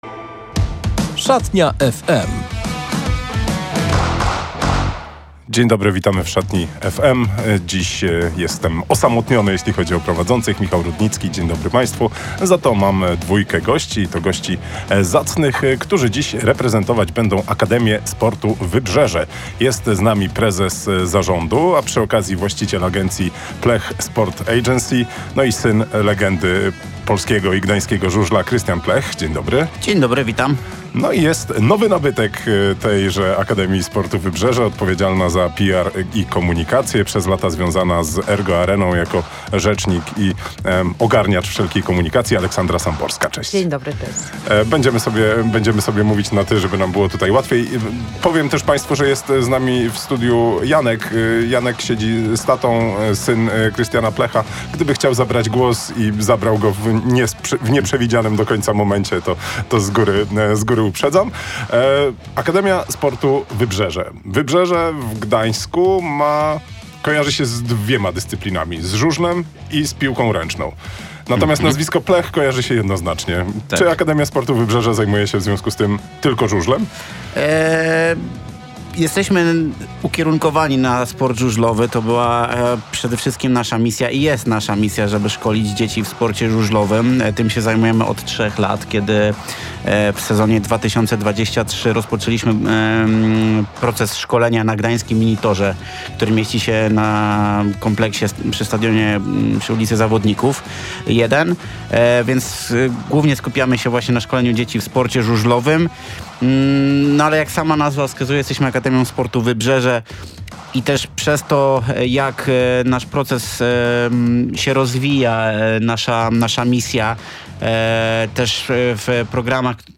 Zanim zaczną swoje „mistrzowskie” półkolonie w Pruszczu Gdańskim, odwiedzili naszą radiową „Szatnię”.